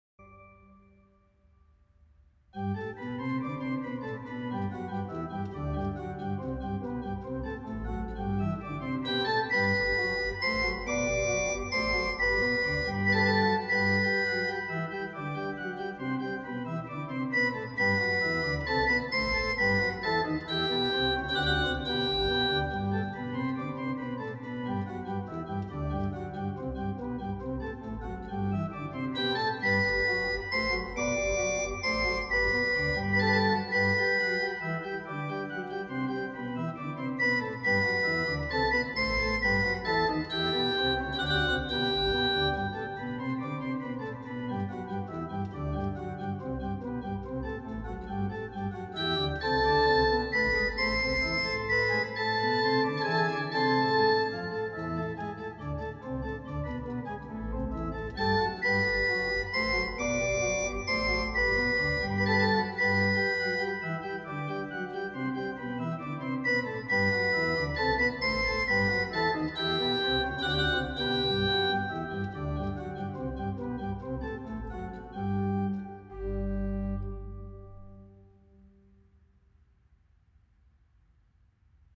Several works for organ of Kirnberger’s hand survive in print and manuscript.
It is a simple but effective bicinium. The recording was done on the sample set of the Van Dam organ (1832) in Tholen by Voxus Organs for Hauptwerk.